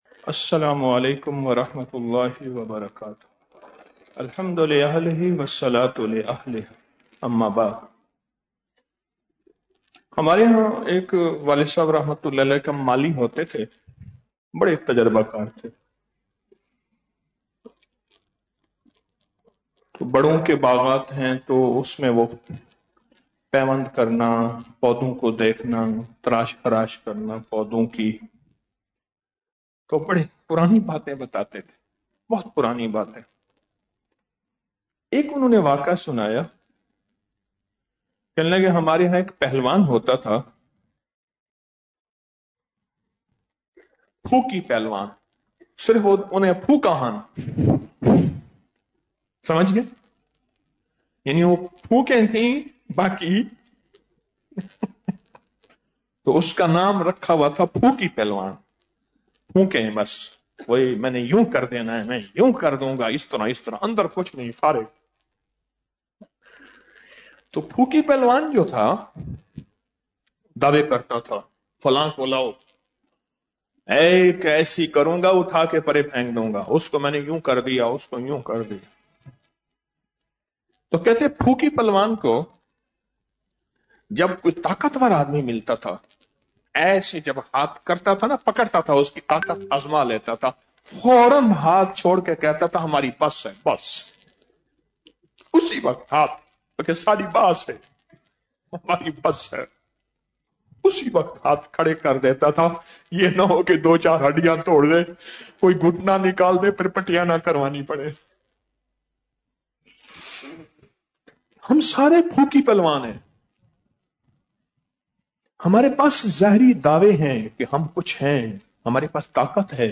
Audio Speech - 22 Ramadan After Salat Ul Taraweeh - 22 March 2025